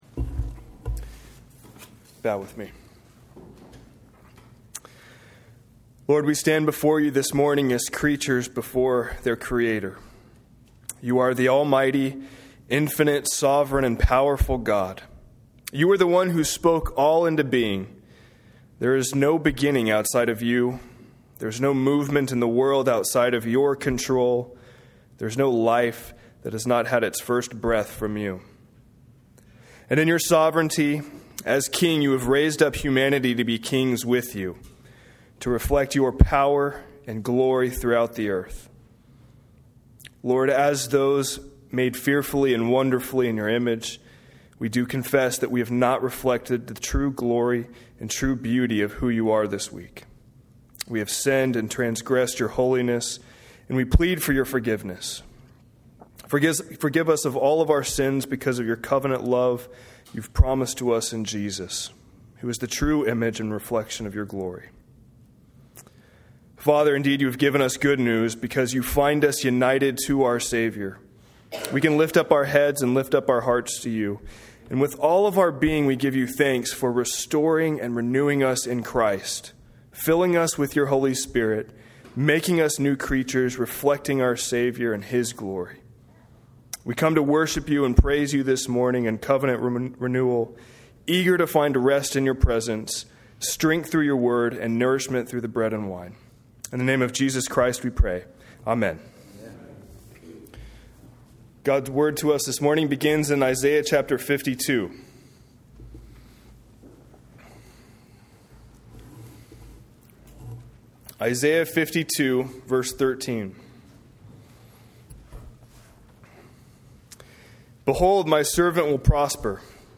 A message from the series "Seasonal Sermons."